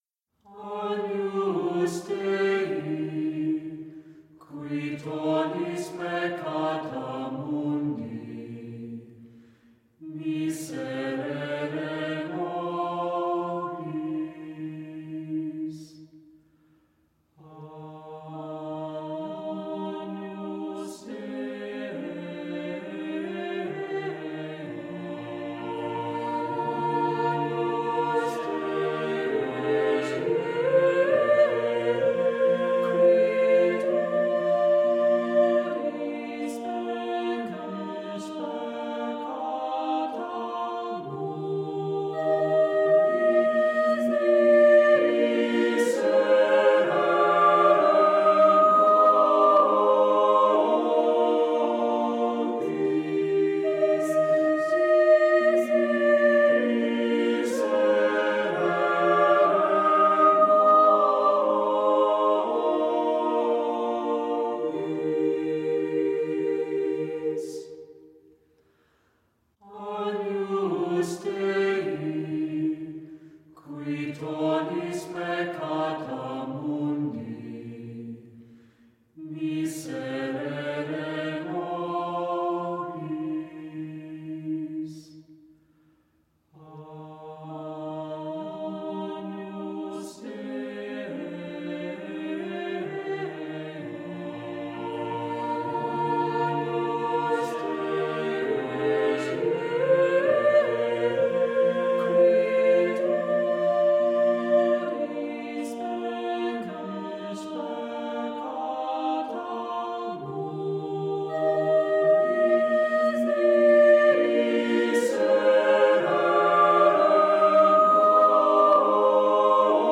Accompaniment:      A Cappella
Music Category:      Early Music